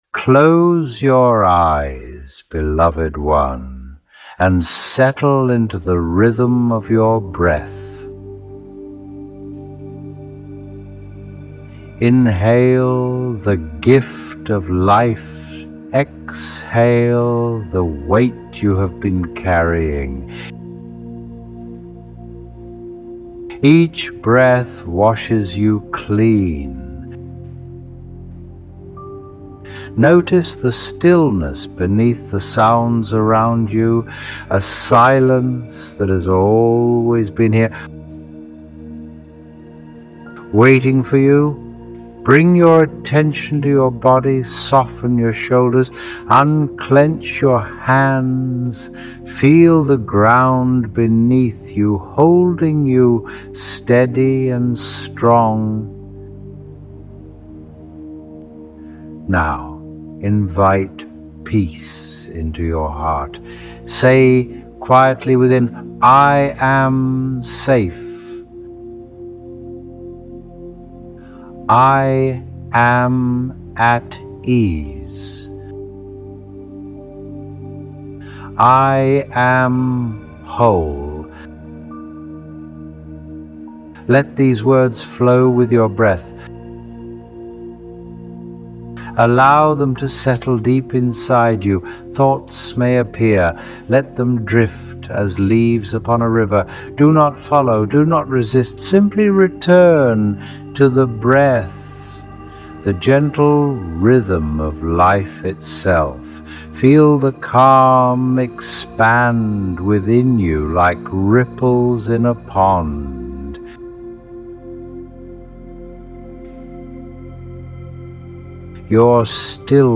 Guided Meditations (Audio)